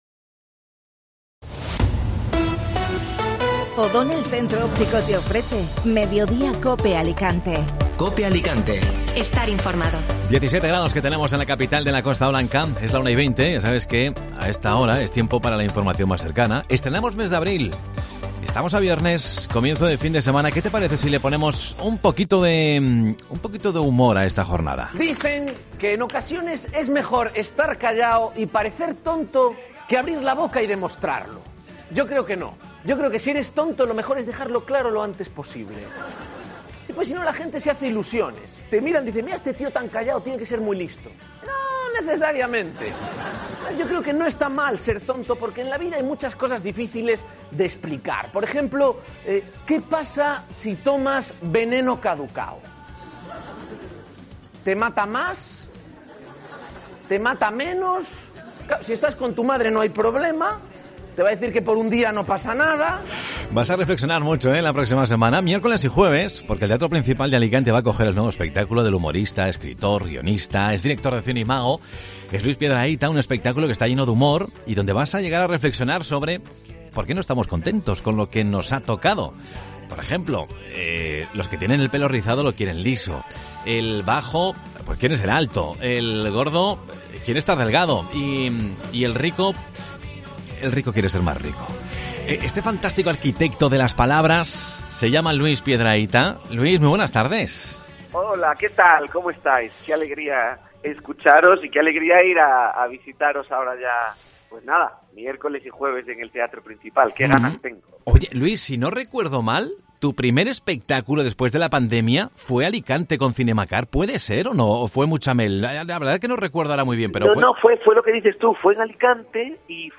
AUDIO: El humorista regresa a Alicante con un espectáculo donde analiza por qué nadie está contento con lo que le ha tocado. Escucha la entrevista en...